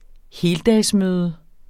Udtale [ ˈheːldas- ]